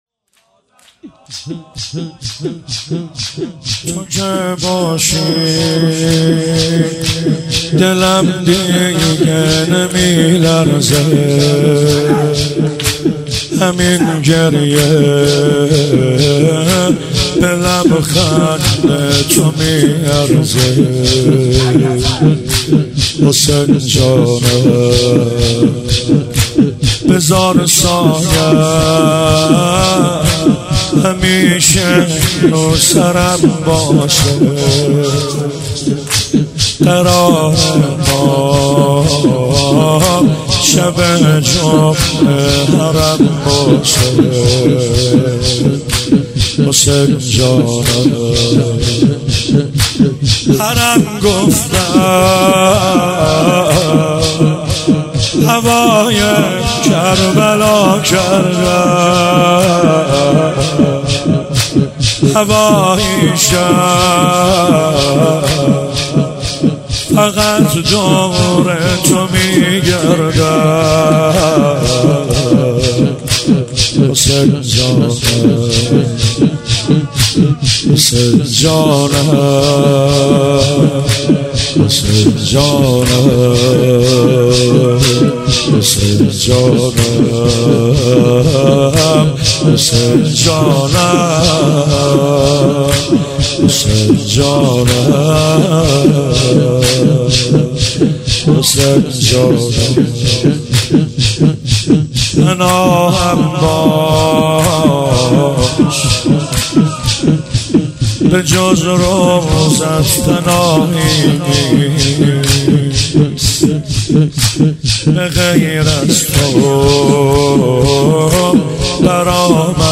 مداحی محرم